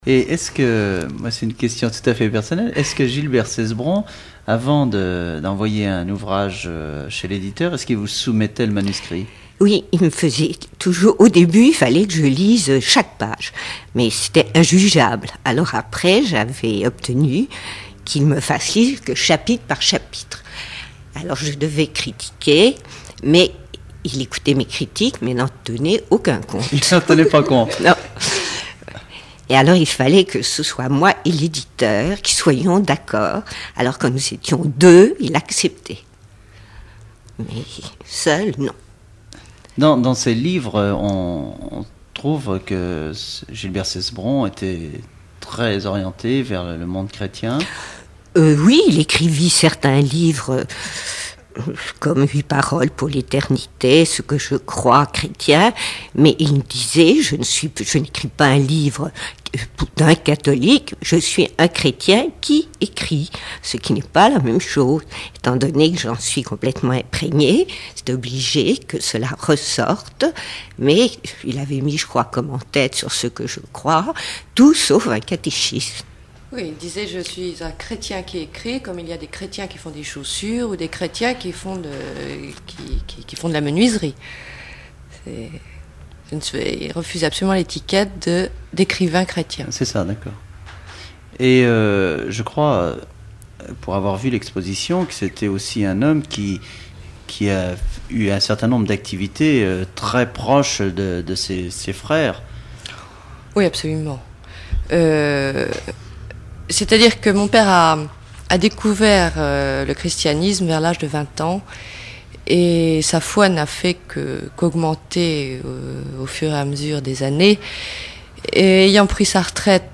Enquête Alouette FM numérisation d'émissions
Catégorie Témoignage